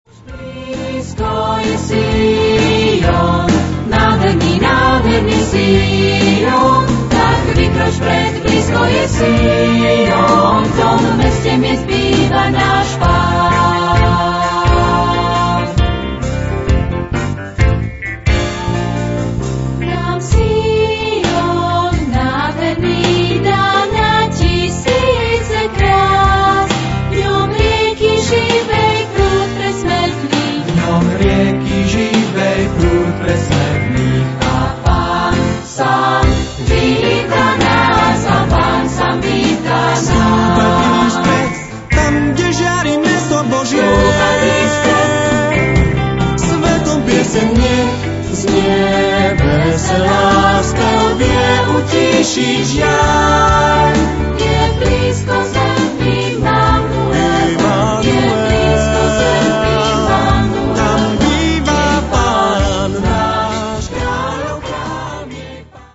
pôvodný slovenský muzikál